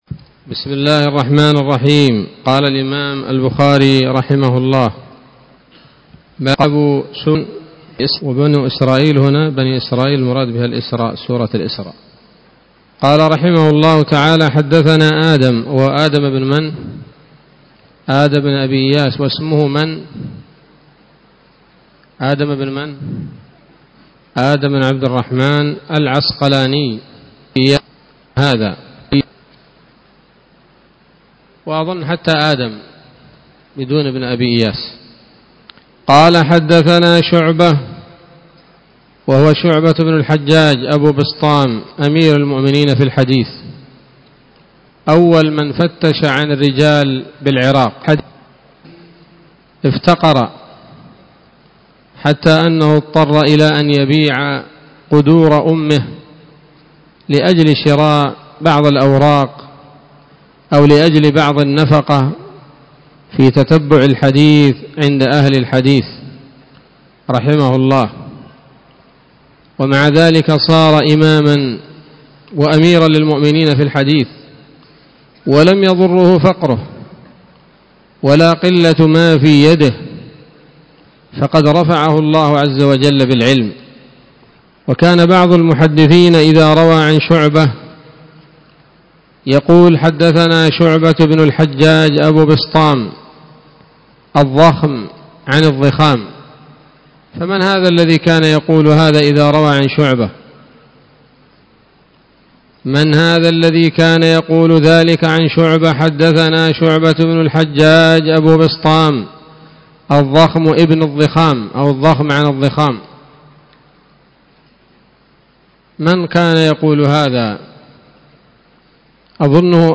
الدرس الثاني والخمسون بعد المائة من كتاب التفسير من صحيح الإمام البخاري